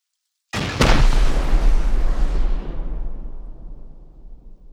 One Rocket Shot Sound Effect Free Download